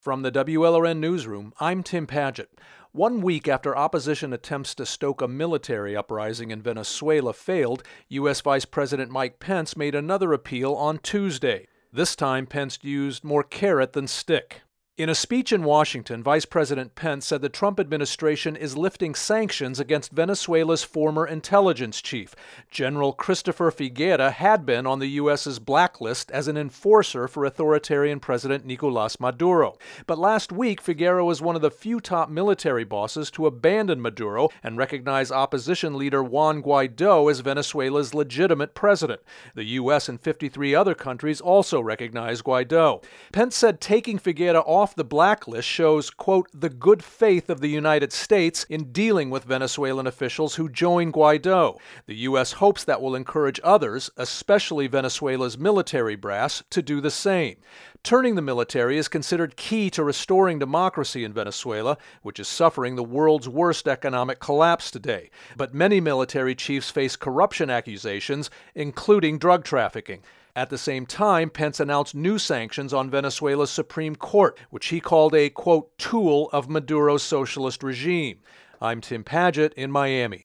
Vice President Mike Pence speaking on Venezuela in Washington on Tuesday.